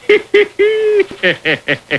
Listen To Homer Laughing By
hehehe.wav